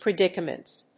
発音記号
• / prɪˈdɪkʌmʌnts(米国英語)